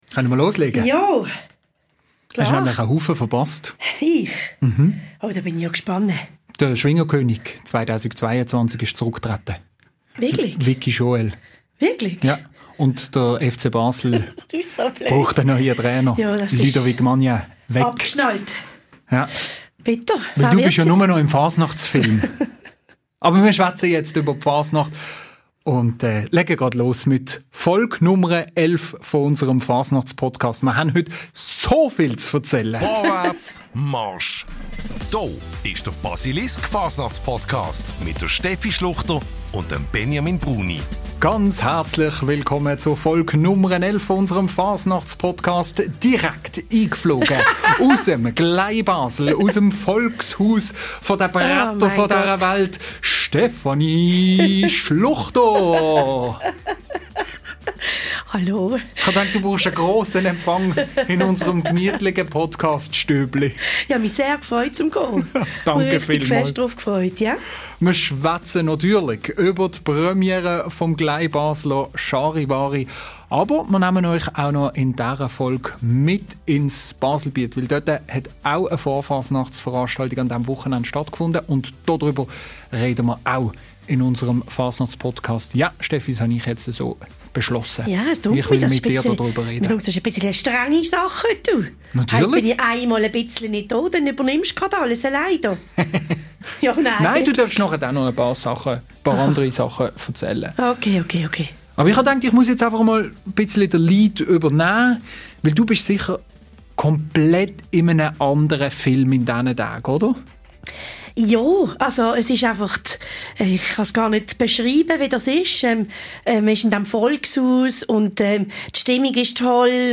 Überraschende Töne hören wir zudem von der Guggenmusik «d Ohregribler».
Viel Fasnachtsmusik, überraschende Einlagen und beste Stimmung – auch hier können wir nur sagen: Gratulation!